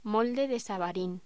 Locución: Molde de savarín
voz